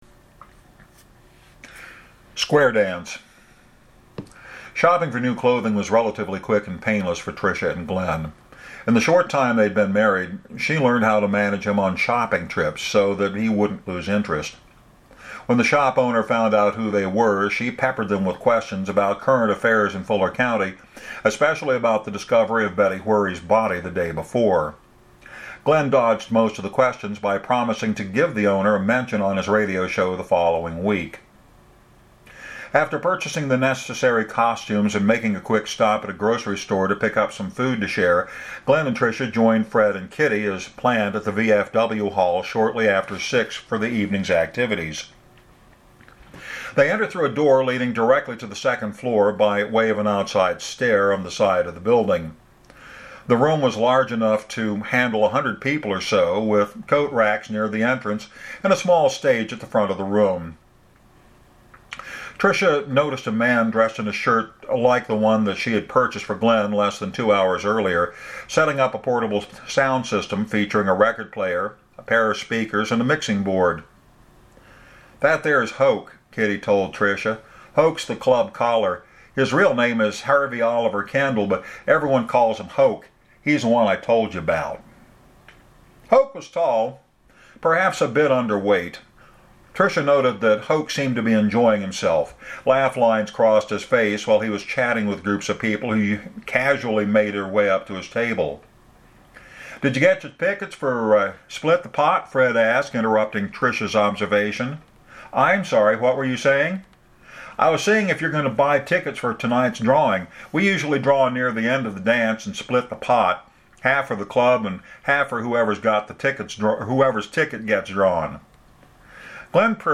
Today’s reading is somewhat long.